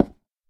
latest / assets / minecraft / sounds / dig / wood1.ogg
wood1.ogg